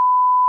1000Hz.wav